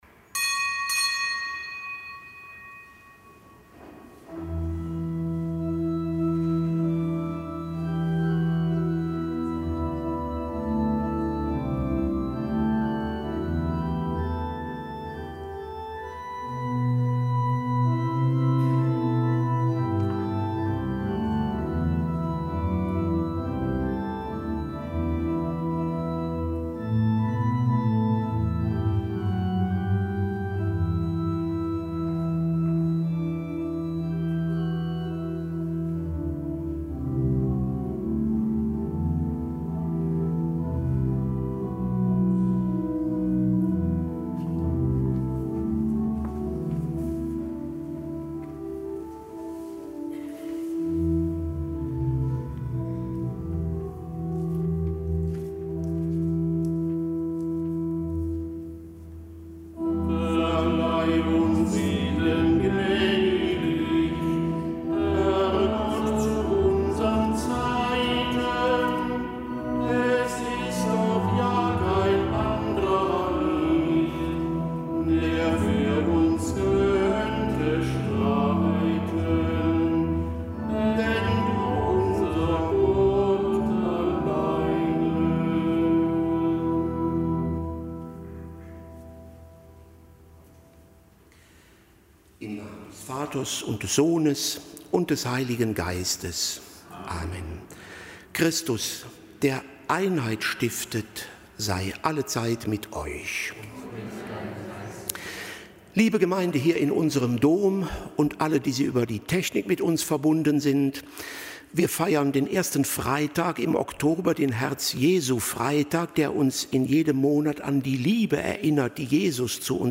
Kapitelsmesse aus dem Kölner Dom am Freitag der sechsundzwanzigsten Woche im Jahreskreis, einem Herz-Jesu-Freitag.